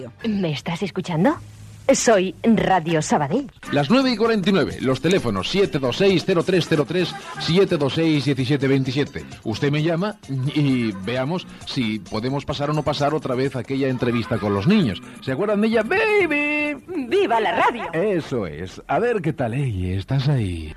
Indicatiu de l'emissora, hora, telèfons, indicatiu del programa
Entreteniment